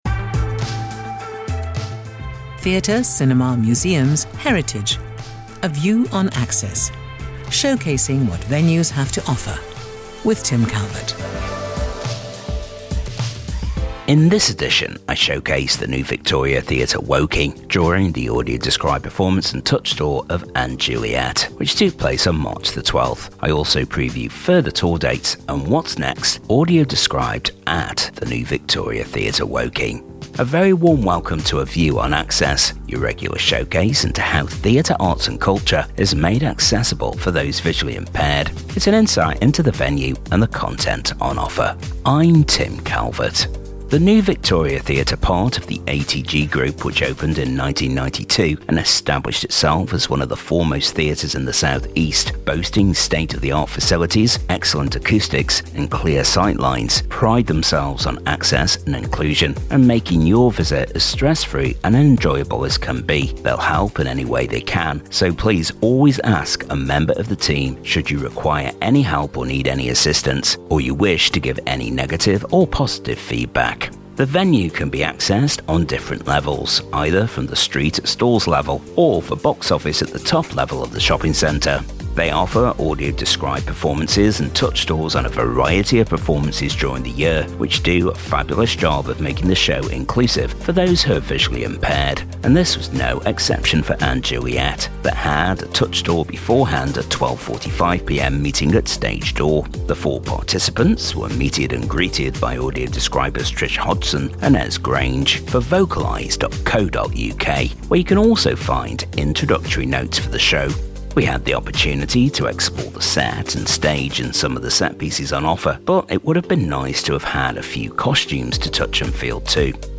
In this edition I showcase the New Victoria Theatre Woking during the audio described performance and touch tour of & Juliet on March the 12th and preview further tour dates and what's next Audio described at Woking